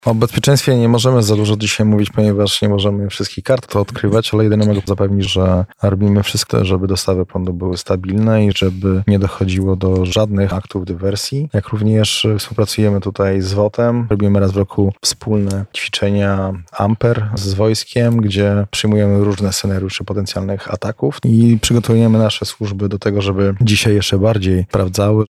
Pełną Poranną Rozmowę Radia Centrum znajdziecie na naszym kanale Spotify.